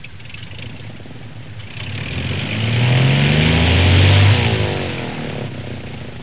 دانلود آهنگ طیاره 31 از افکت صوتی حمل و نقل
جلوه های صوتی
دانلود صدای طیاره 31 از ساعد نیوز با لینک مستقیم و کیفیت بالا